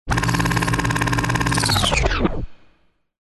На этой странице собраны звуки кинопроектора в высоком качестве – от мягкого гула до характерных щелчков пленки.
Звук остановки кинопленки в старом проекторе